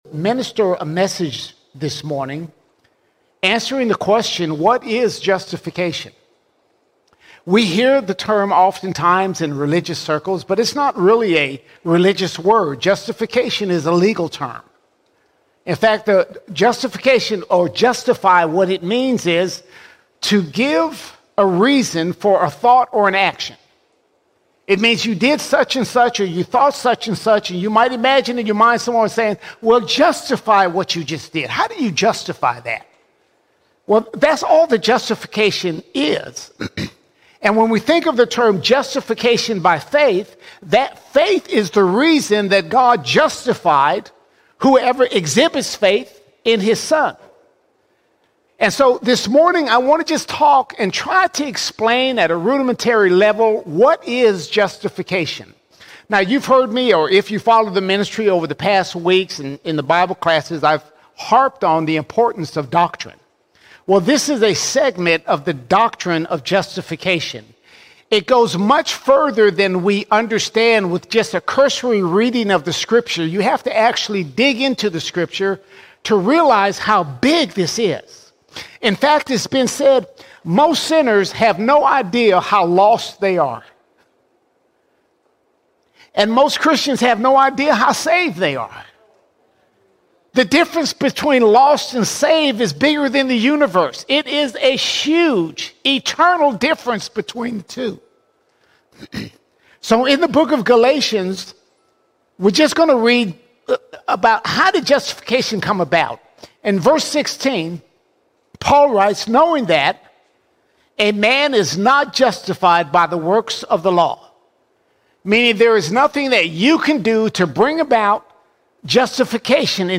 21 July 2025 Series: Sunday Sermons All Sermons What Is Justification What Is Justification When we put our faith in Jesus, God gives us what feels impossible!